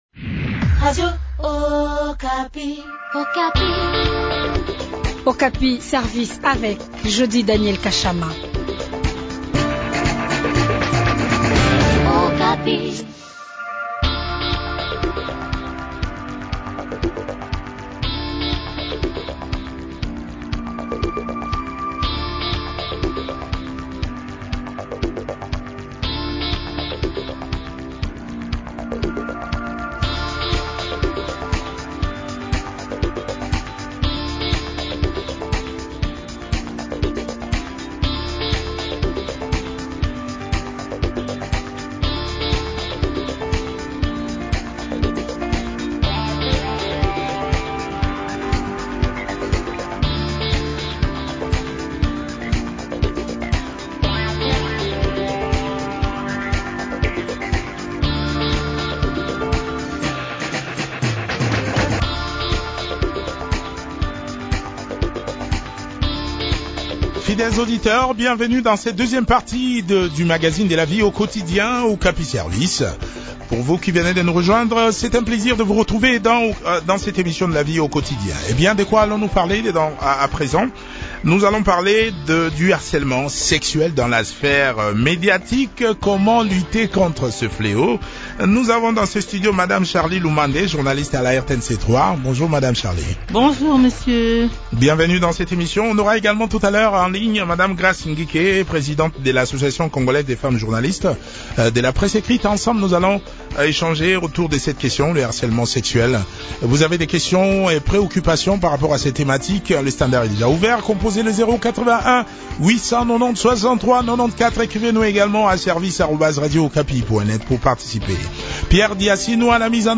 a aussi pris part à cette interview.